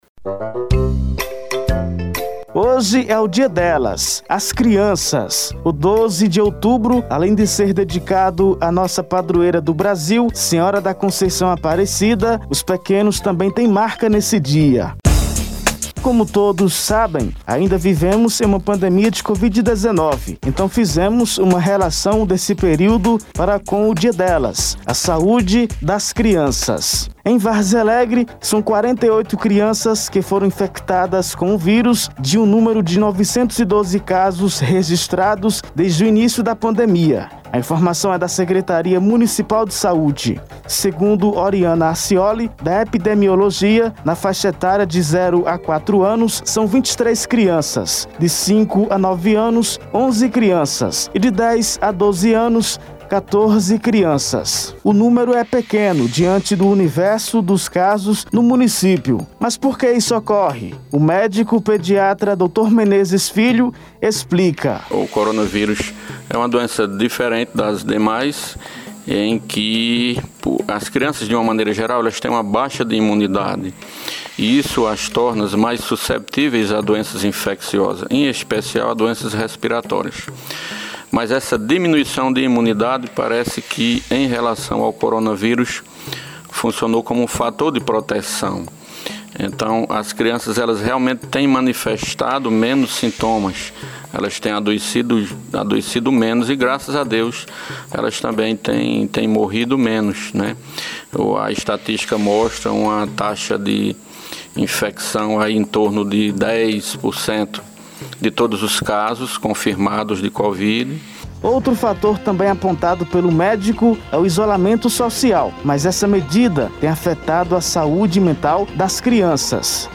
A reportagem especial da Rádio Cultura desta segunda-feira, 12, revelou que mais de 40 crianças foram infectadas pela Covid-19 no município de Várzea Alegre ao longo da pandemia.